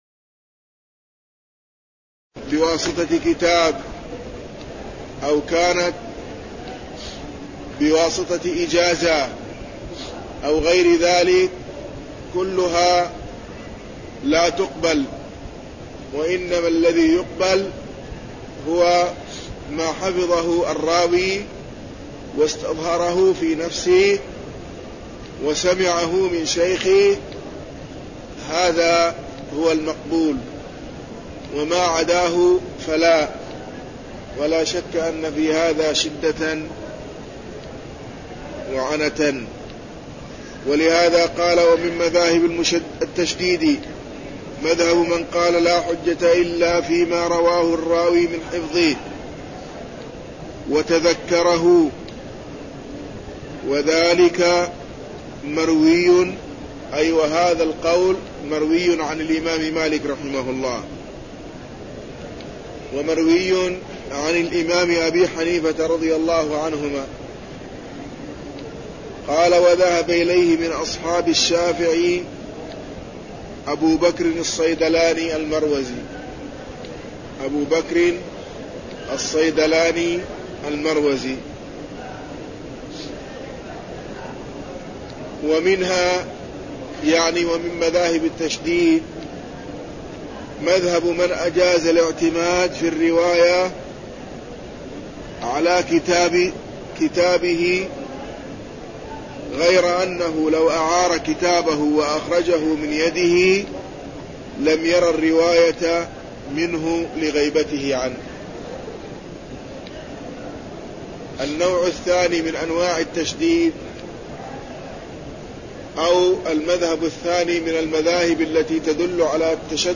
درس عن رواة الحديث (027)
المكان: المسجد النبوي